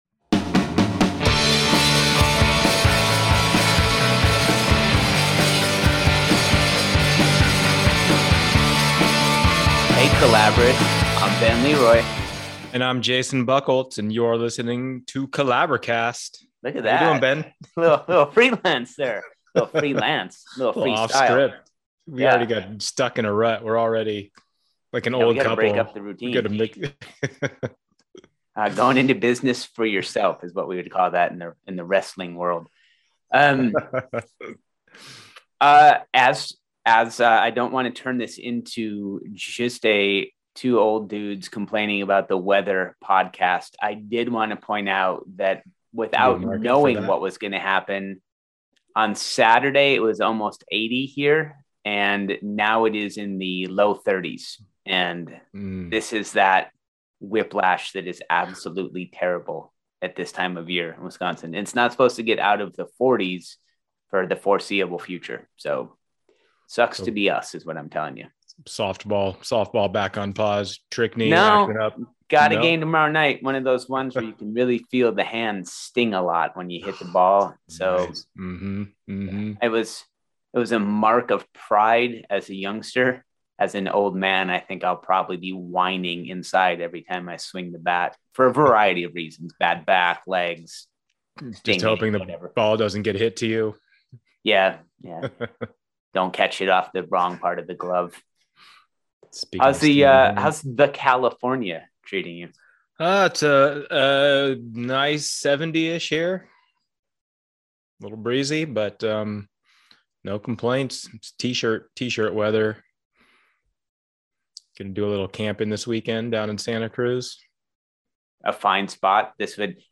We provide a list of things YOU can do to make sure that your goals are in line with the capabilities of a publisher. This conversation will help you avoid disappointment and feel informed about how publishing works and the role you can play in your own journey.